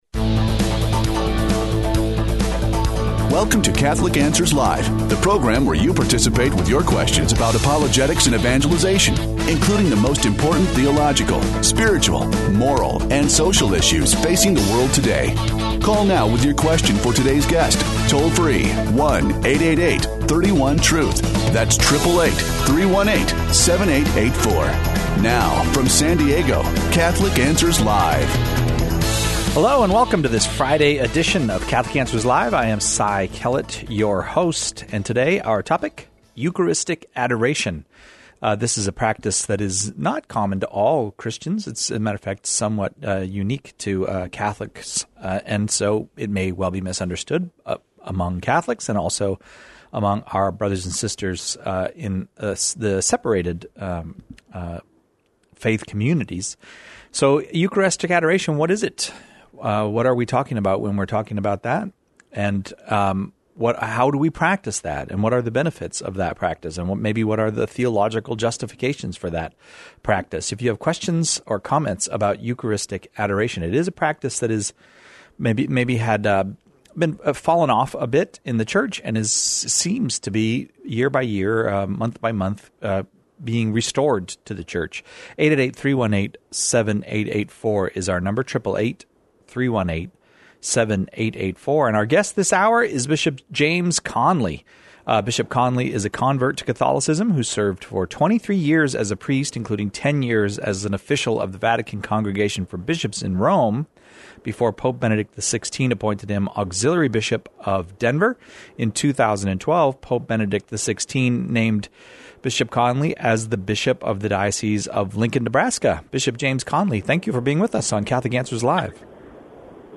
Lincoln Nebraska Bishop James Conley discusses his recent pastoral letter, “Love Made Visible” and takes calls on the role of Eucharistic Adoration in the lives...